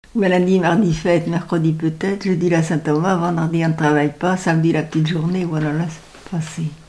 Enumératives
Pièce musicale inédite